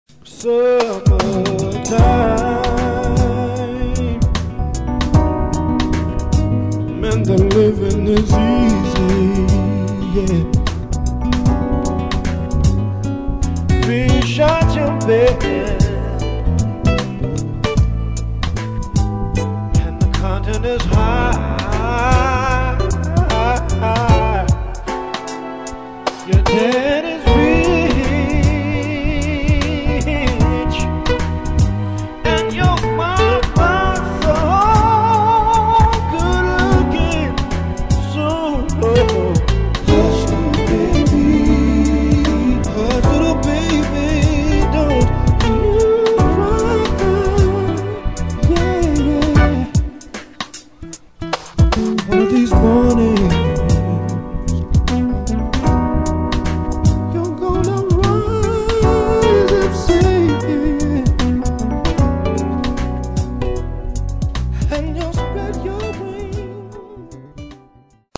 Jazz/Funk/ R&B
bassist/trumpeter/vocalist
multi-talented guitarist/lead vocalist
has established a sound that is sophisticated and funky!
the cutting edge blend of Jazz/Funk–call it Neo-Jazz